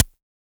Index of /musicradar/retro-drum-machine-samples/Drums Hits/Tape Path B
RDM_TapeB_SY1-Snr03.wav